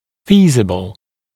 [‘fiːzəbl][‘фи:зэбл]реальный, выполнимый, осуществимый